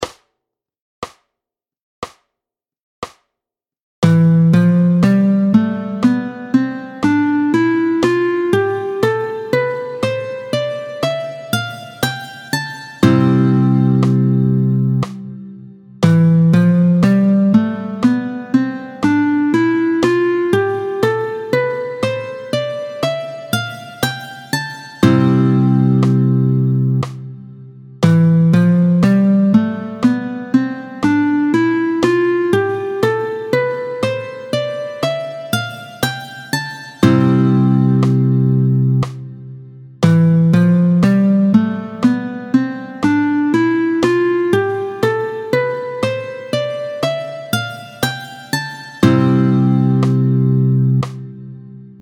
26-09 Doigté 3, Do majeur, tempo 60